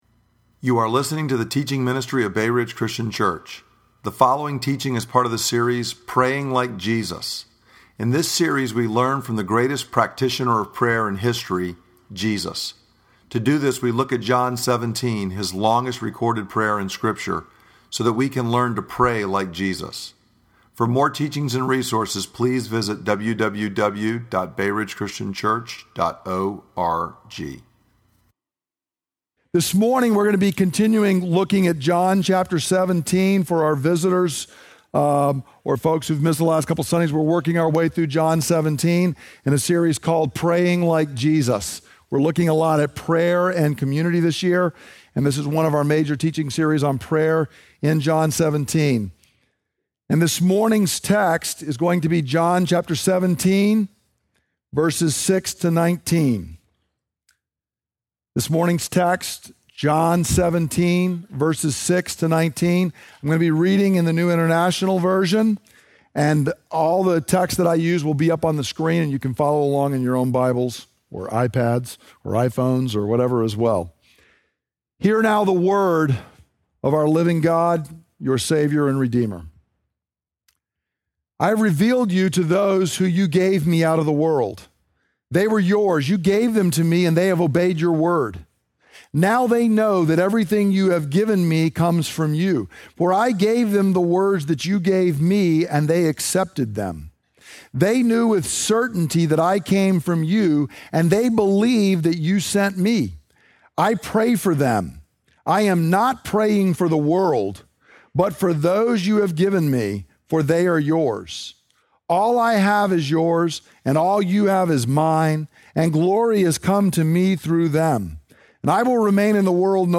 John 17:6-19 Listen to the teaching Look at the outline June 30